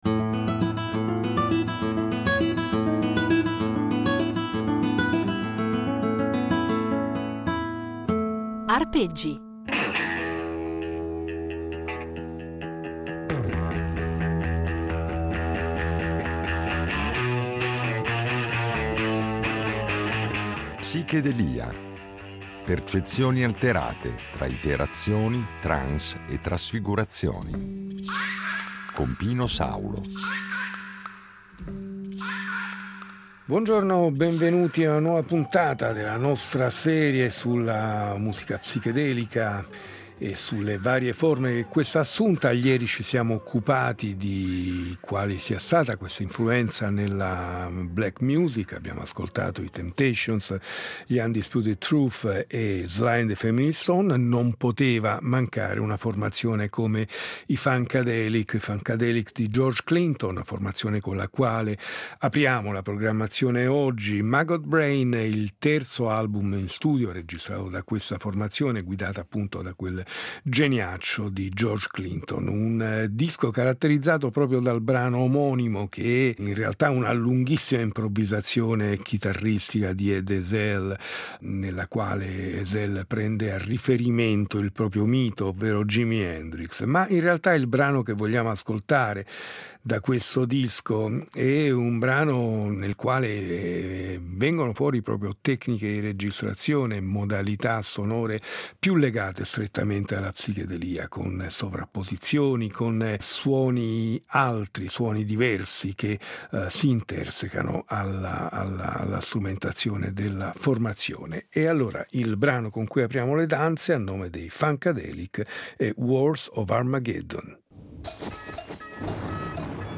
Termine volutamente ambiguo, che va a indicare un insieme di musiche nate intorno alla metà degli anni ‘60 aventi in comune un riferimento alle droghe allucinogene, ma nelle quali la musica incorpora elementi provenienti da altre culture musicali - dall’oriente soprattutto, con l’utilizzo di strumenti come il sitar o le tabla - effetti sonori, tecniche di registrazione che pongono in qualche modo elevano lo studio di registrazione al rango di uno strumento al pari degli altri, con l’uso massiccio di loop, nastri al contrario, riverbero, e ancora elementi del jazz modale e più sperimentale, droni, il tutto condito da luci stroboscopiche e testi surreali o con riferimenti più o meno velati a esperienze allucinatorie.